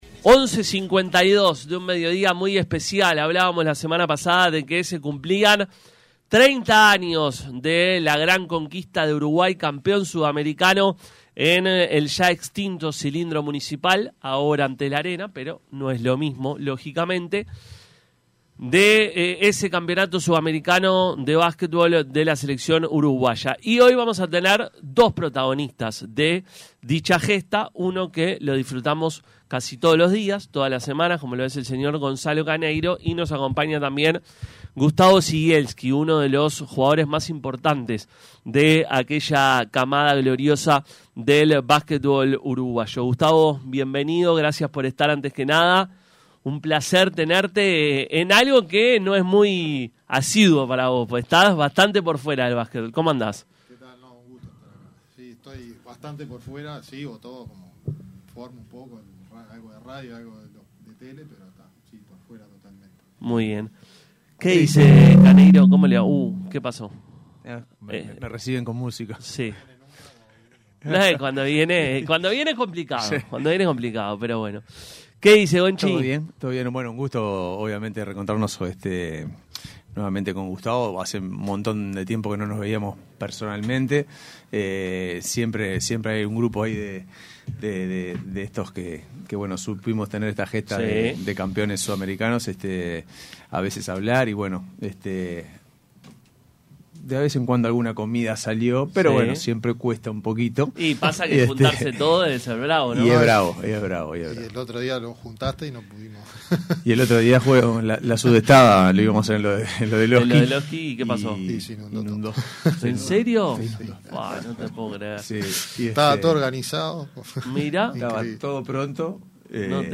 nos visitó en los estudios de Radio Universal y habló de todo con el equipo de Pica La Naranja.